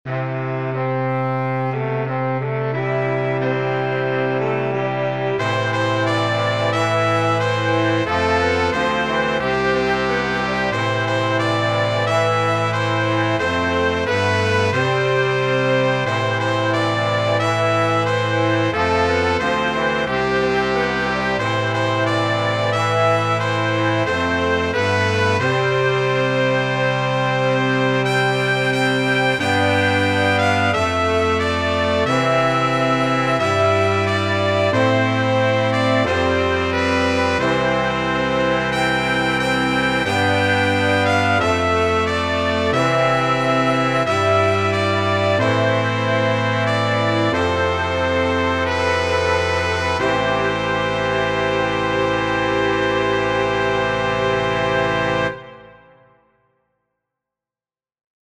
Recently I have talked with my high school's band director about composing an alma mater for our school because we've never had one. I was inspired both lyrically and musically by traditional church hymns (my school is a Christian school).